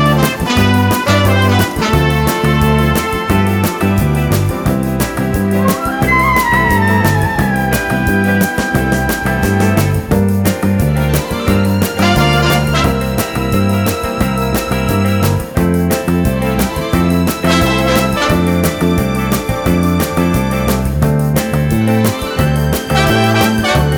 Dance Mix Crooners 3:51 Buy £1.50